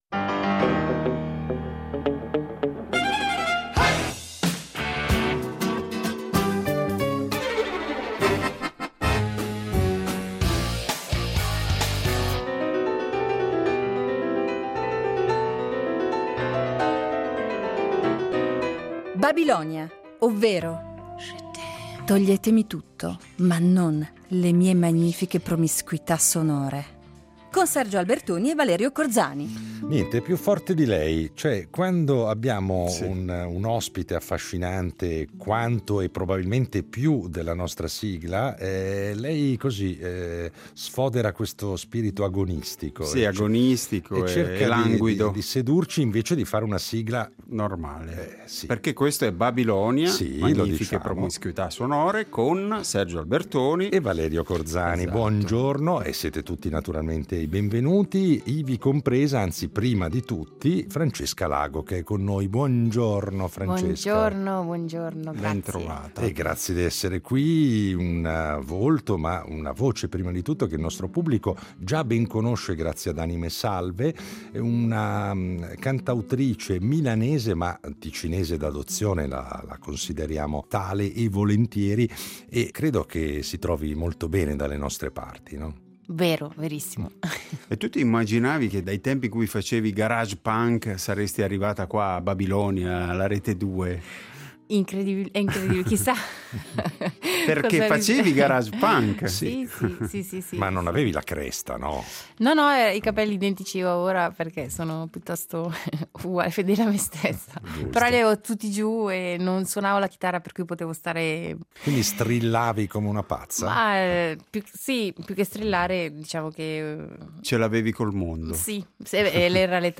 Un'artista a suo modo babilonica, quindi, per un itinerario sonoro come sempre variegato e ricco di sorprese.